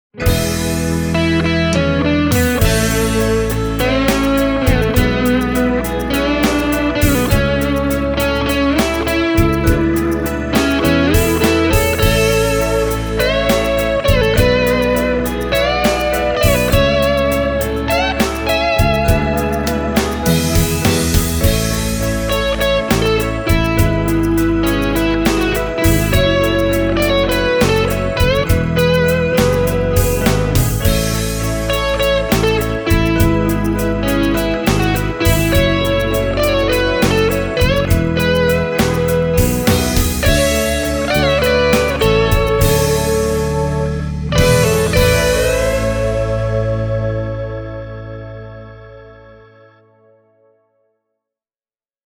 Vahvistimen läpi tämä pieni ero kuitenkin häviää, ja Edwardsin ja referessikitaran väliset pienet soundilliset erot johtuvat varmaan enemmän soittimien eri mikrofoneista kuin niiden perusäänistä.